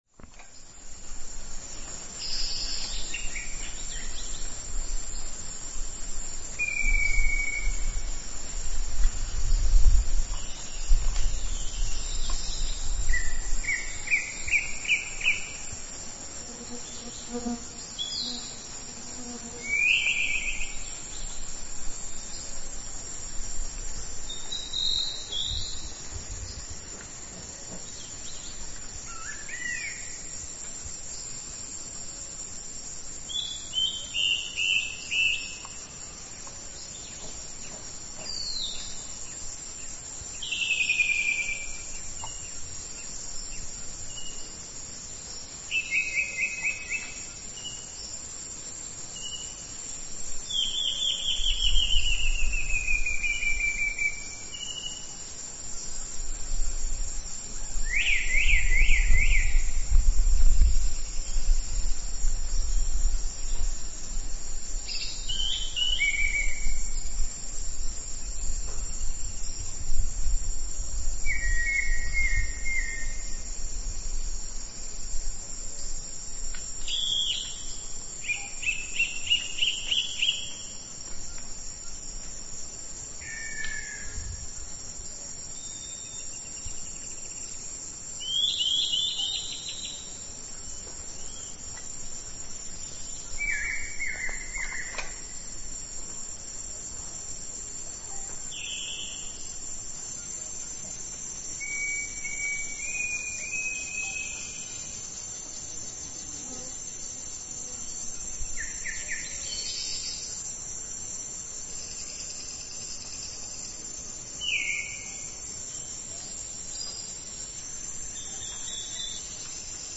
The other is a Lawrence’s thrush, a close relative of the American robin, and its song is a collection of mimicry, innovative phrases, car alarm sounds (there are no cars in the jungle, so this would have to be convergent evolution) and the opening notes of the Hallelujah chorus from Beethoven’s 9th symphony.
The talented Lawrence's Thrush, singing a symphony.
Sound file: Lawrence’s Thrush
lawrencesthrushlong.mp3